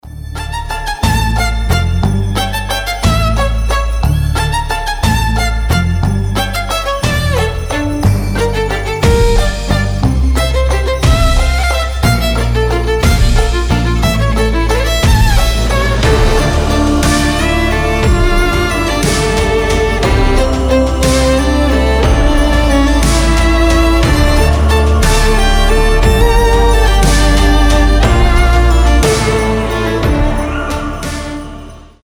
• Качество: 320, Stereo
красивые
без слов
скрипка
рождественские
Neoclassical